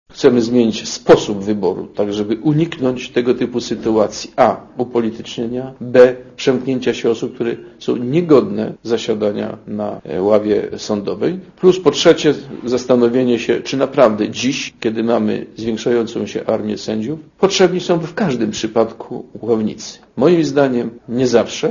Mówi minister Kurczuk (84kB)